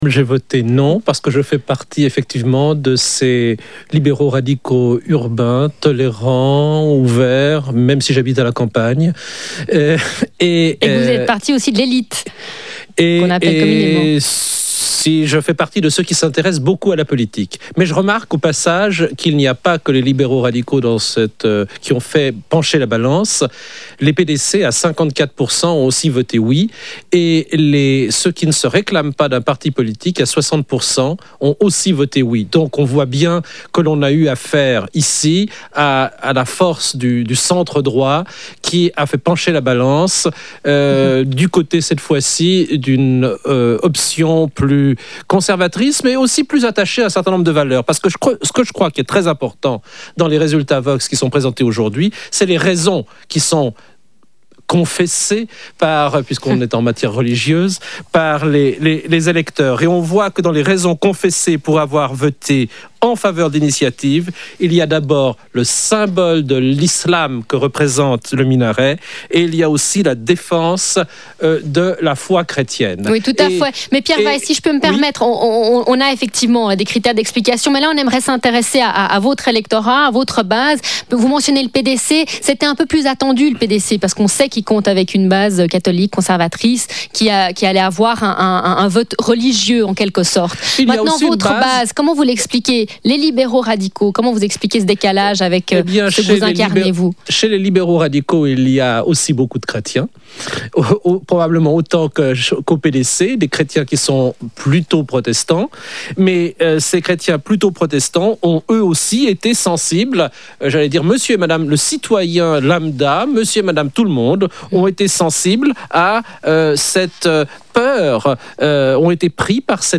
Pierre Weiss, vice-président du PLR (Parti libéral-radical / droite)